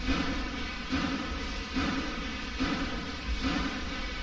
crush.wav